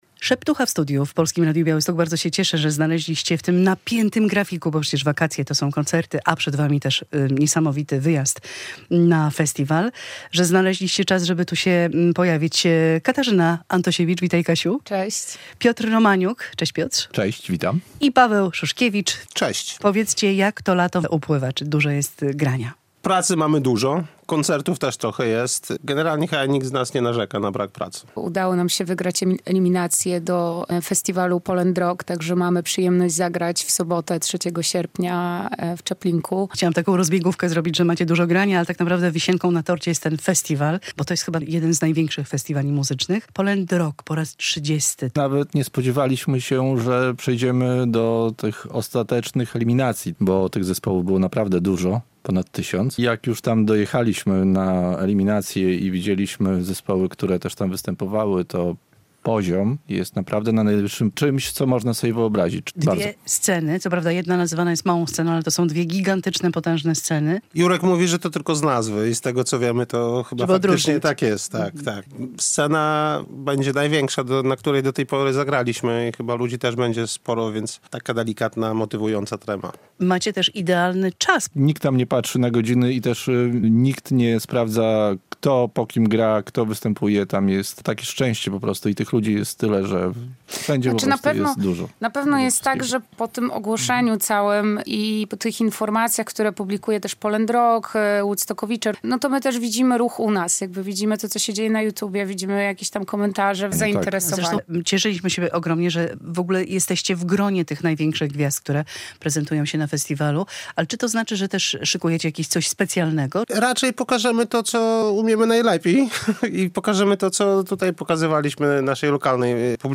Z członkami zespołu Szeptucha rozmawia